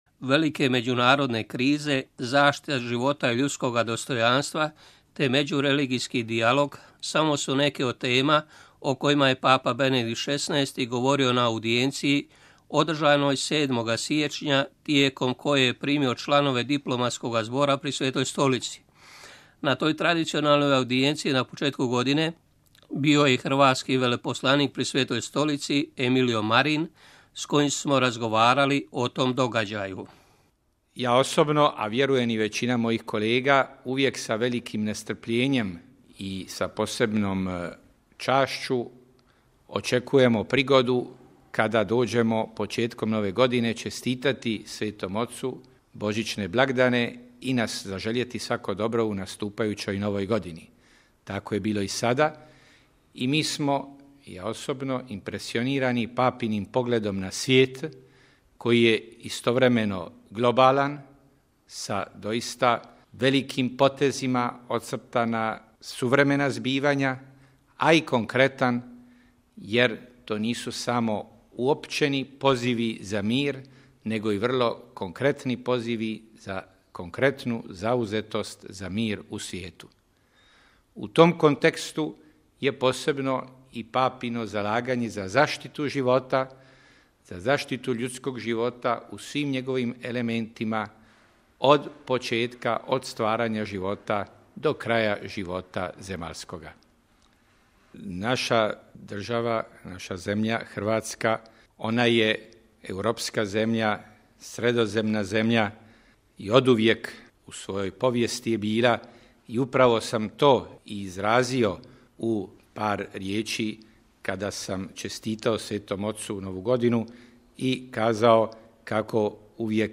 Razgovor s hrvatskim veleposlanikom pri Svetoj Stolici
Velike međunarodne krize, zaštita života i ljudskoga dostojanstva, te međureligijski dijalog – samo su neke od tema o kojima je papa Benedikt XVI. govorio na audijenciji, održanoj 7. siječnja, tijekom koje je primio članove Diplomatskoga zbora pri Svetoj Stolici. Na toj tradicionalnoj audijenciji na početku godine bio je i hrvatski veleposlanik pri Svetoj Stolici Emilio Marin, s kojim smo razgovarali o tom događaju.